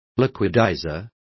Complete with pronunciation of the translation of liquidizers.